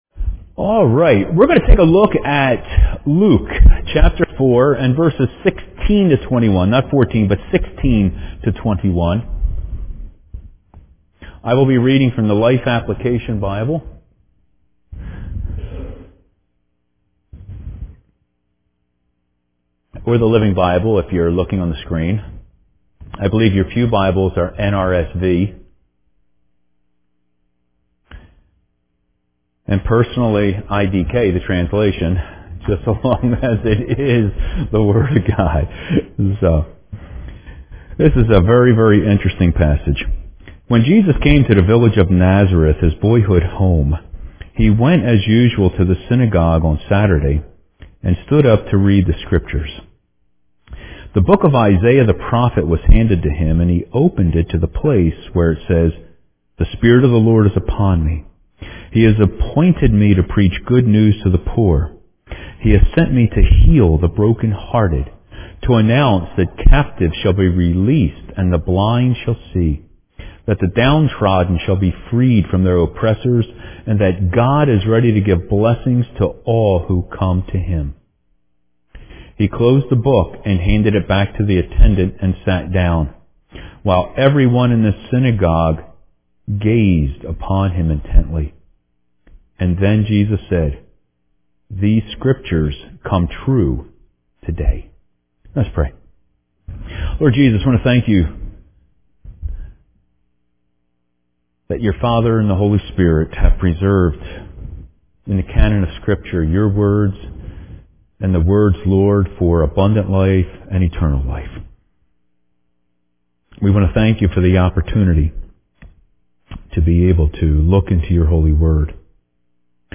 The recording is from the 11:00 service…